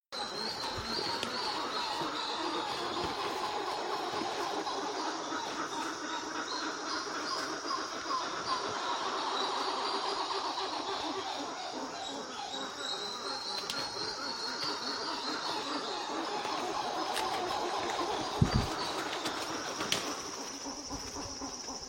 Coppery Titi (Plecturocebus cupreus)
Country: Ecuador
Location or protected area: Parque Nacional Yasuní
Certainty: Recorded vocal
titi-rojizo.mp3